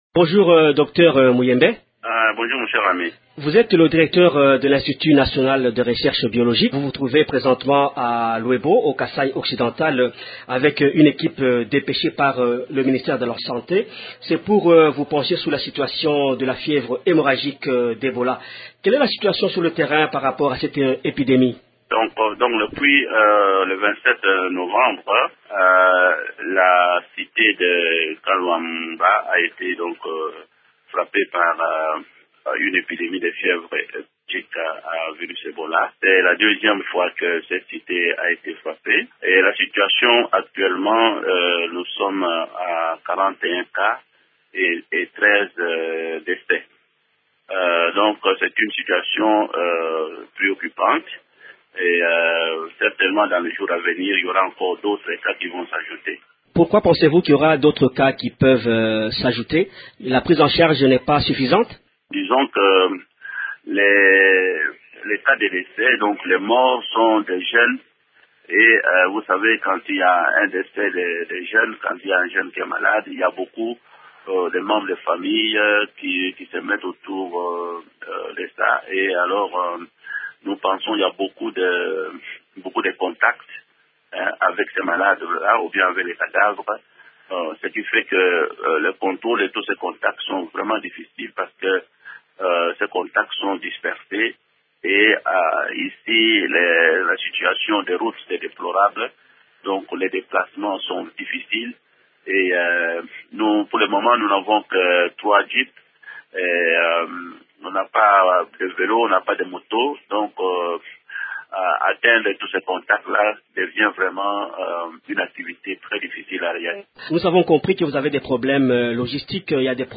Le docteur Muyembe craint que ce bilan puisse s’alourdir à cause des contacts qui se poursuivent entre la population et les personnes atteintes par cette maladie. Le directeur de l’INRB est notre invité.